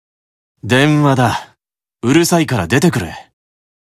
Satan_Call_Notification_(NB)_Voice.ogg